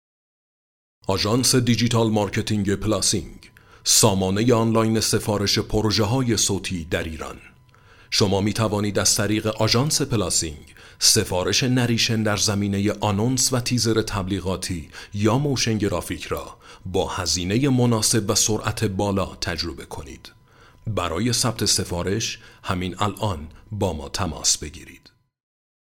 نریتور و گوینده آقا
گوینده آقا کد 230